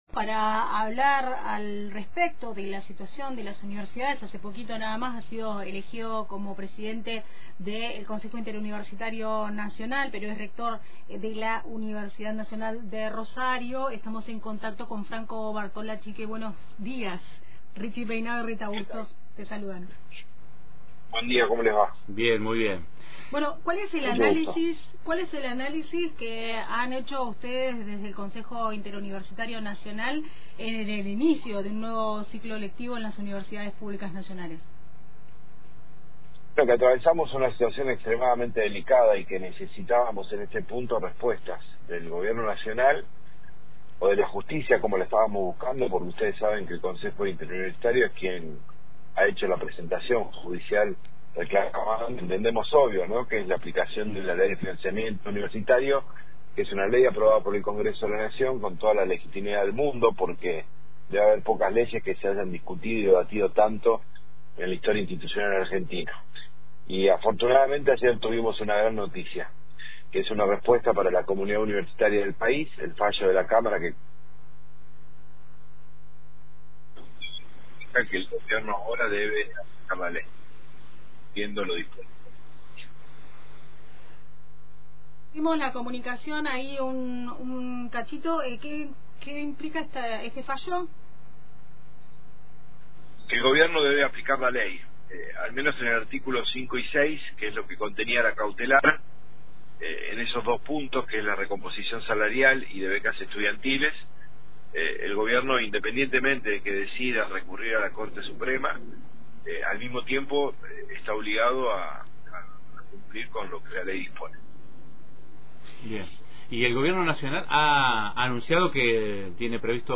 En diálogo con Antena Libre, explicó que la medida cautelar impulsa la aplicación inmediata de los artículos vinculados a la recomposición salarial y a las becas estudiantiles, aun cuando el Ejecutivo decida apelar ante la Corte Suprema.